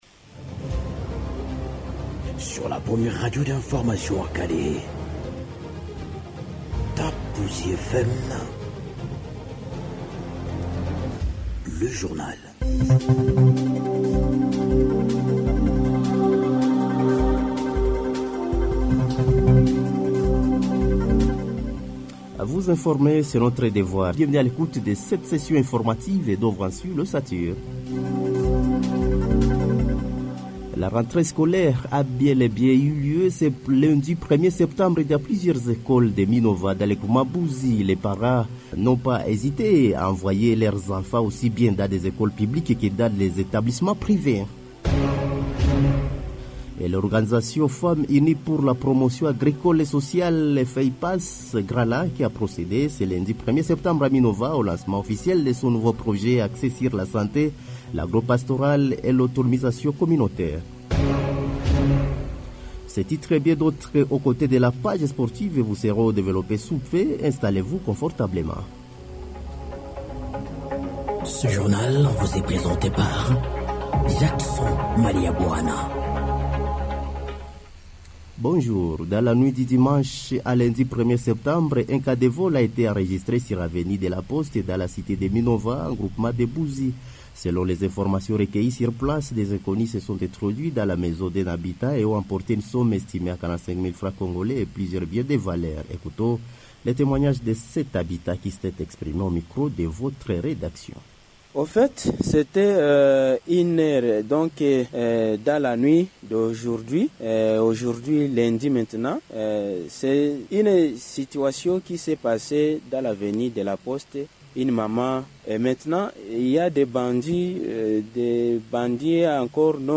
Journal mardi matin 2 septembre 2025
journal-fr-mardi-matin-2-septembre.mp3